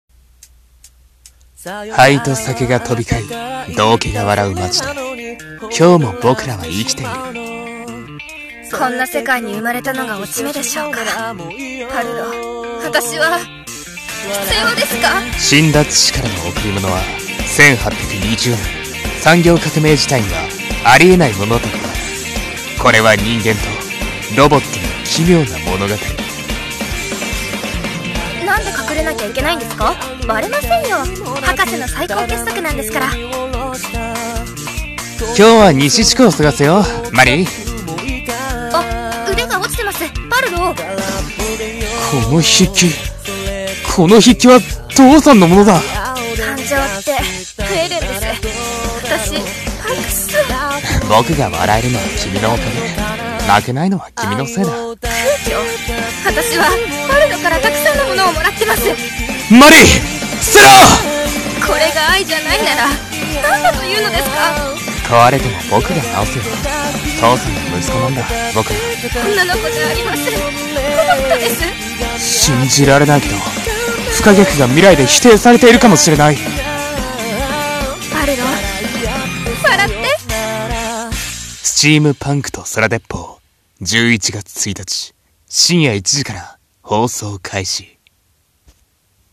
【アニメ告知CM風声劇台本】スチームパンクと空鉄砲【２人声劇】